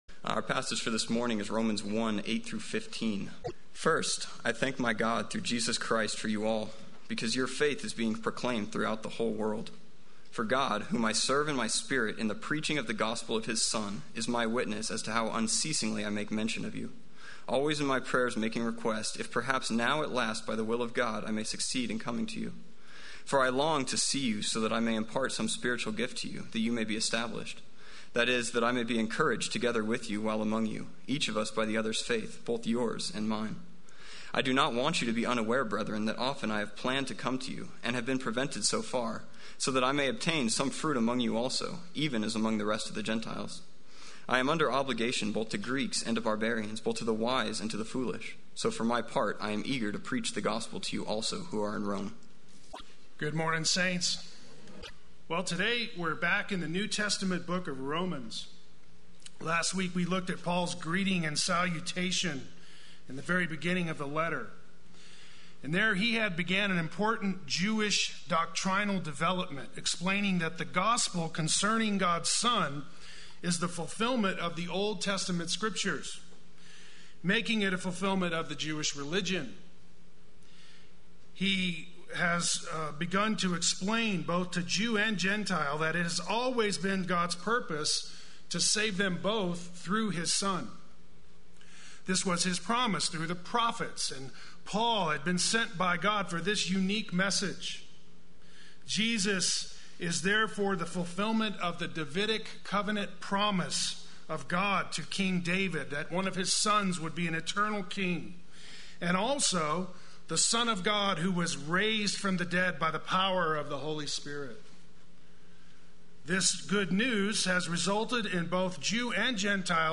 Play Sermon Get HCF Teaching Automatically.
“Eager to Preach the Word” Sunday Worship